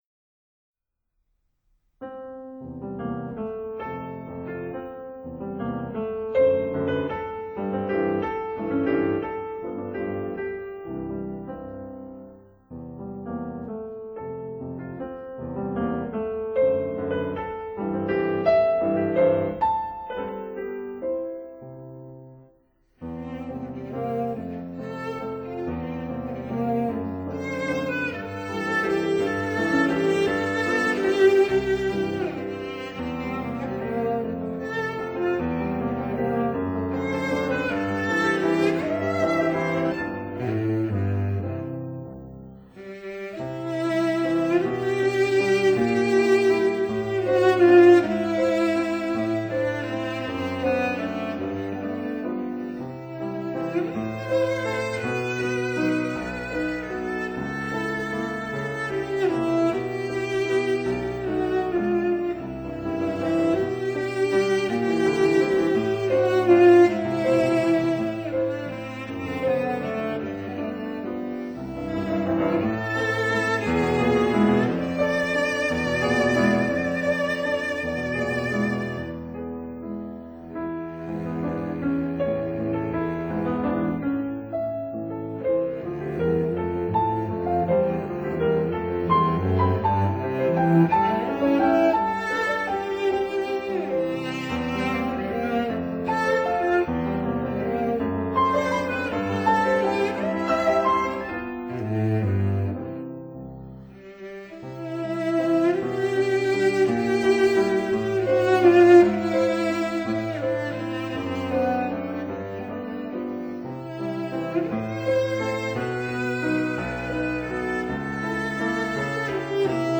violin
cello